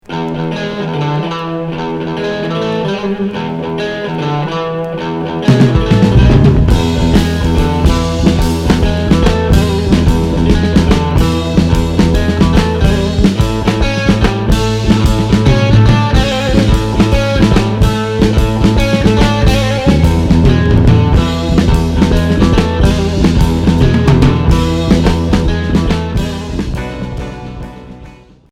Heavy rock Premier 45t retour à l'accueil